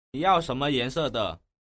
nỉ dao sấn mơ dán xựa tợ?